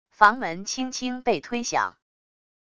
房门轻轻被推响wav音频